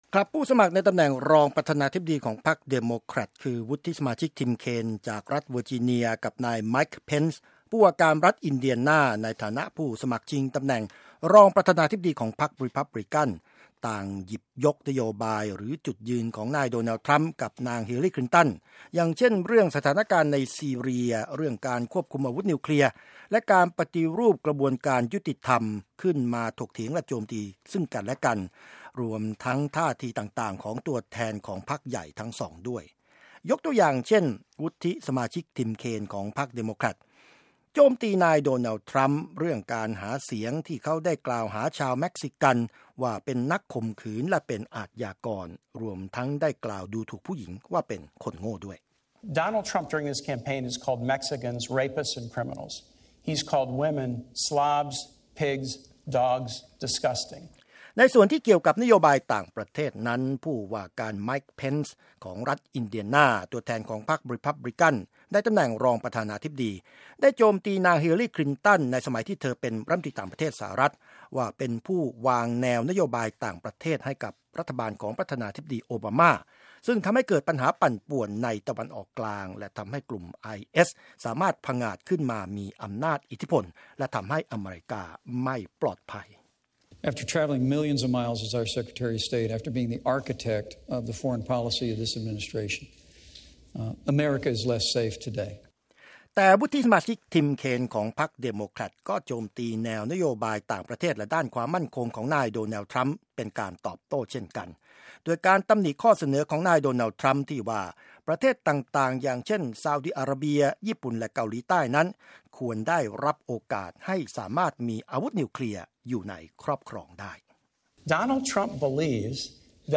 'ผู้สมัครตำแหน่งรองประธานาธิบดี' ขัดจังหวะซึ่งกันและกันระหว่างการดีเบตครั้งแรกและครั้งเดียว
VP Debate